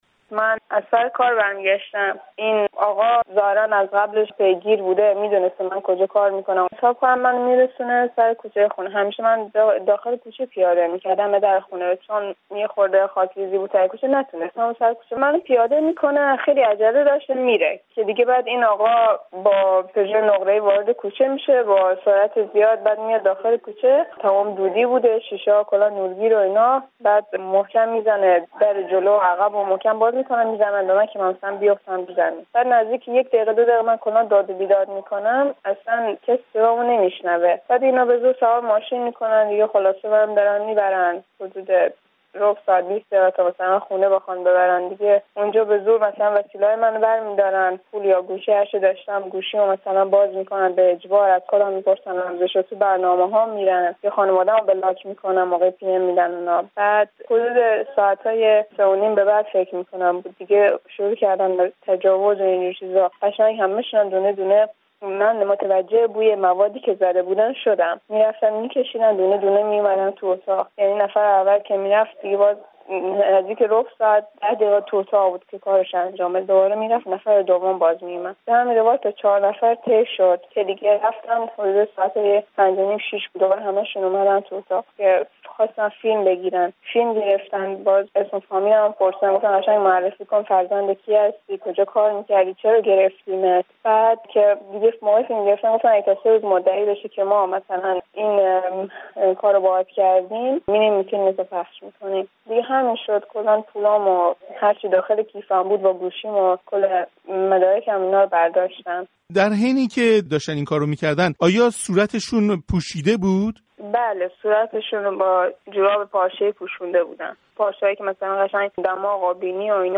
گفت‌وگوی رادیو فردا با یکی از قربانیان تجاوز در ایرانشهر